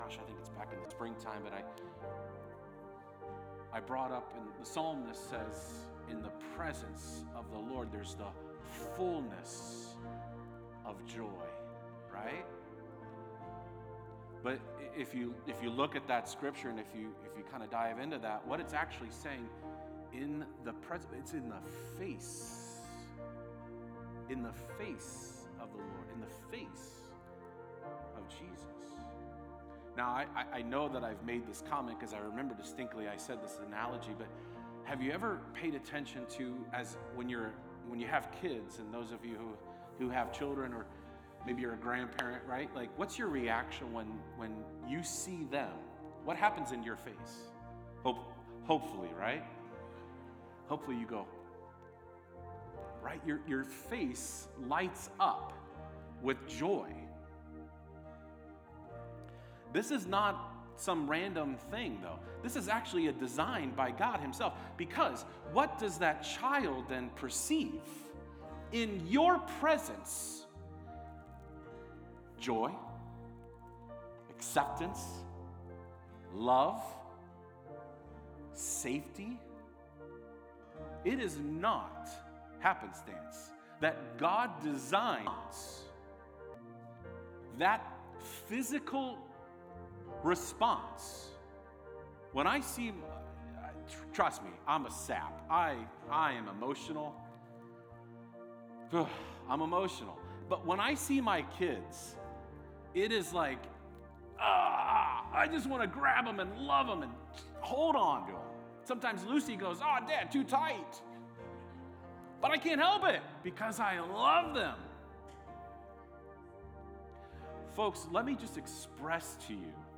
A message from the series "Focus: Our Lens of Life."